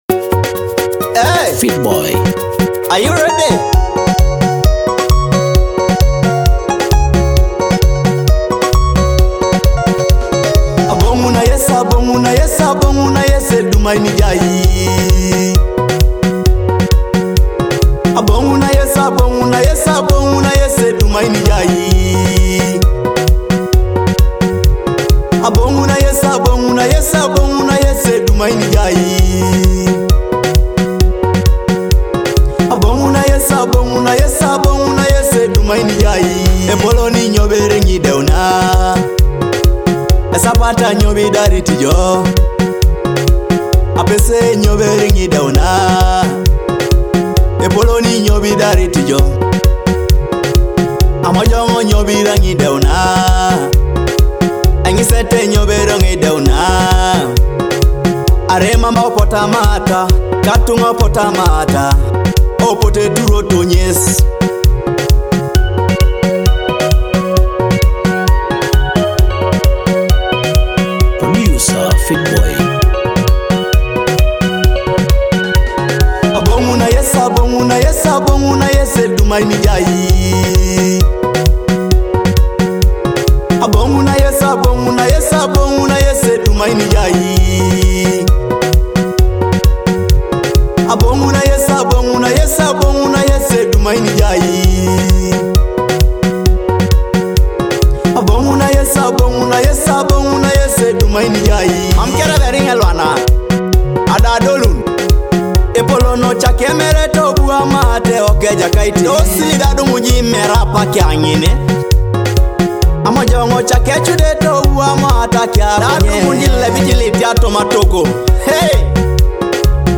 a powerful Afro-gospel song that asks
gospel song